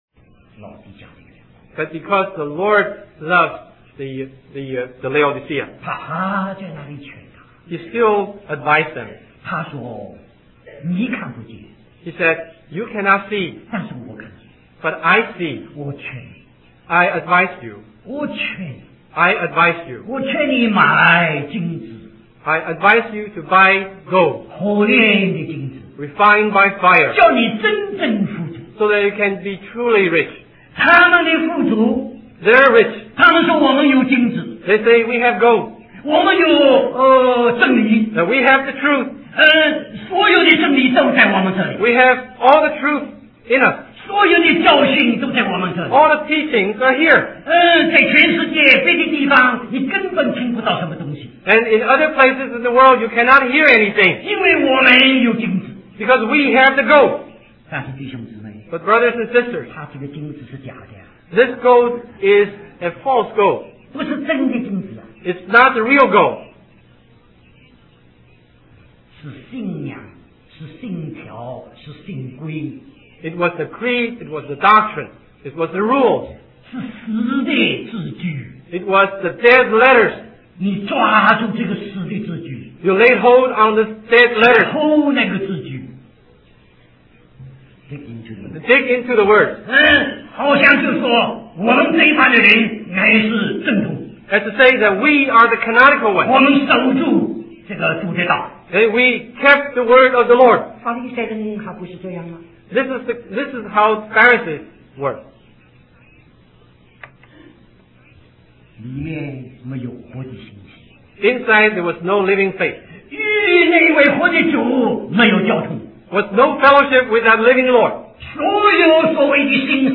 A collection of Christ focused messages published by the Christian Testimony Ministry in Richmond, VA.
Conference at Bible Institute of Los Angeles